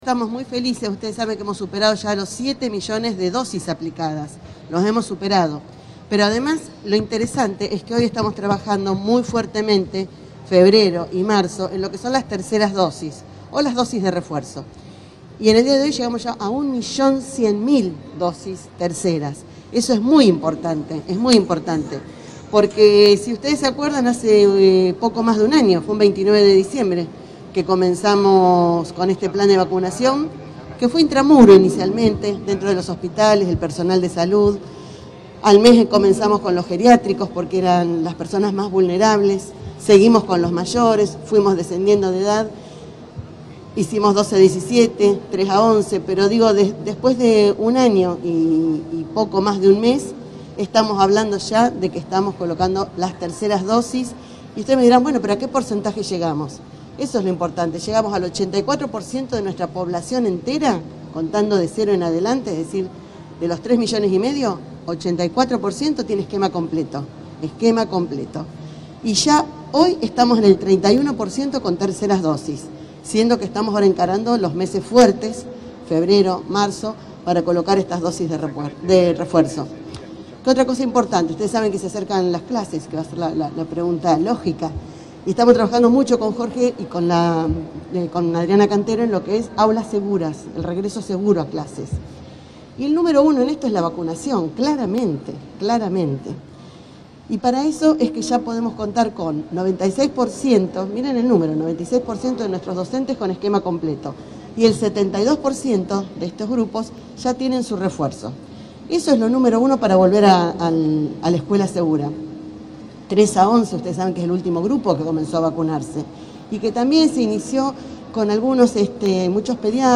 La ministra de Salud, Sonia Martorano, brindó este miércoles una conferencia de prensa junto al secretario de Salud, Jorge Prieto, en el vacunatorio que funciona en la Esquina Encendida de la ciudad de Santa Fe.